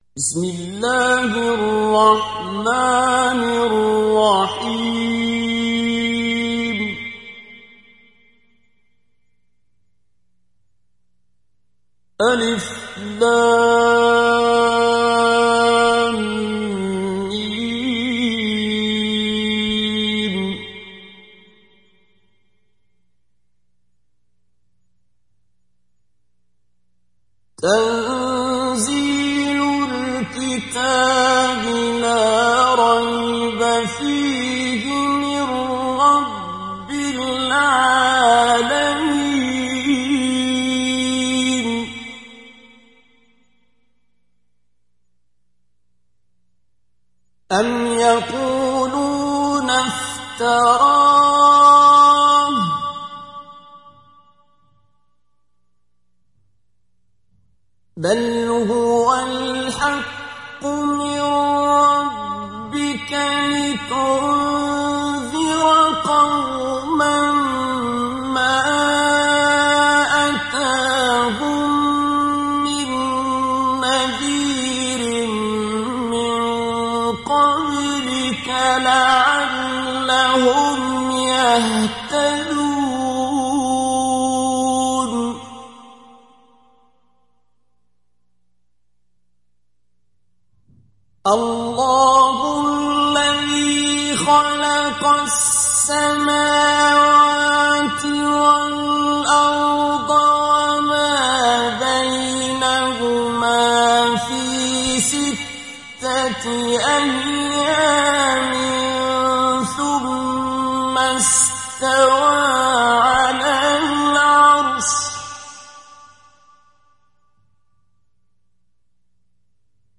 İndir Secde Suresi Abdul Basit Abd Alsamad Mujawwad